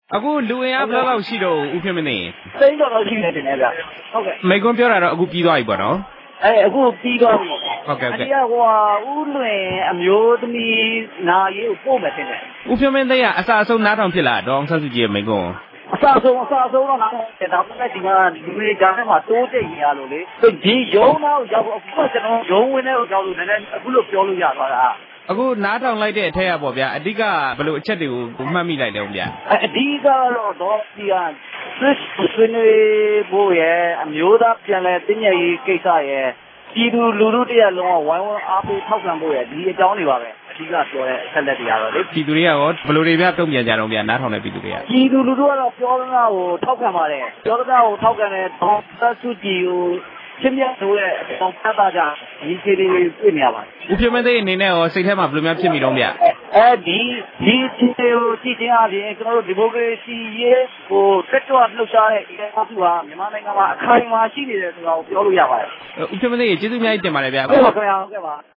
ဒေါ်အောင်ဆန်းစုကြည် ပထမဆုံး မိန့်ခွန်း လူ ၁ သိန်းခန့် တက်ရောက်နားထောင်